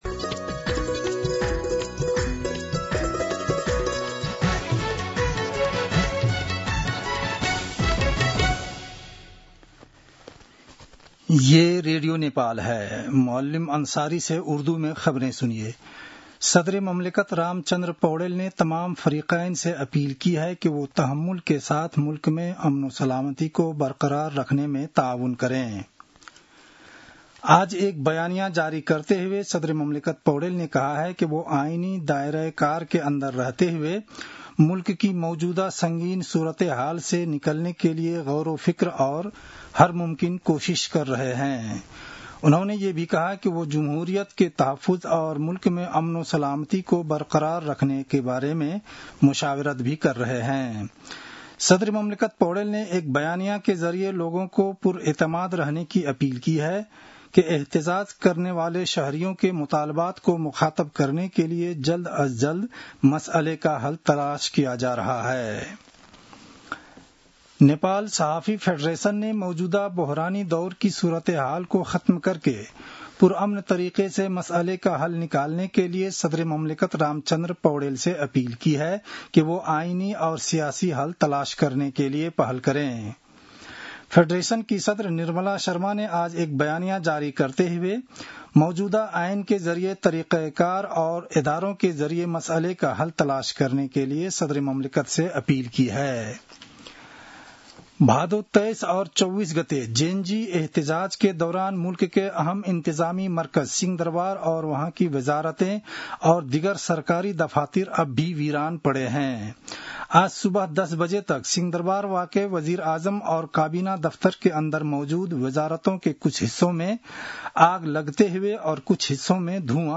उर्दु भाषामा समाचार : २६ भदौ , २०८२